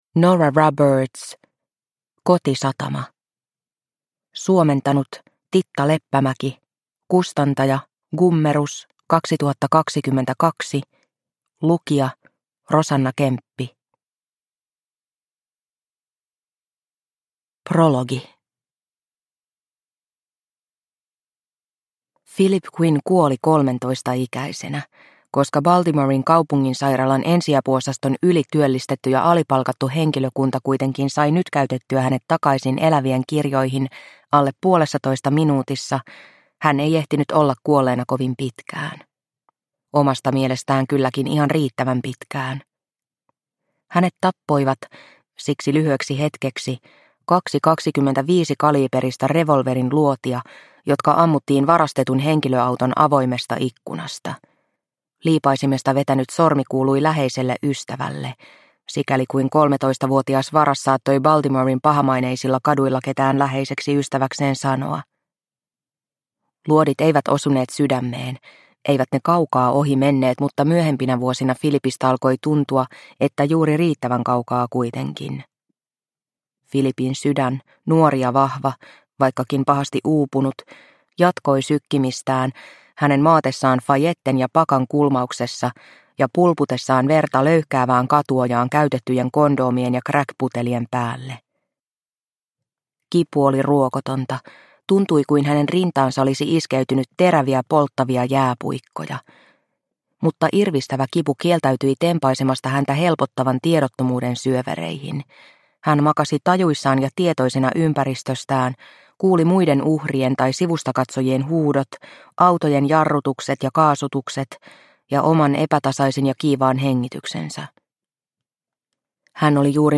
Kotisatama – Ljudbok – Laddas ner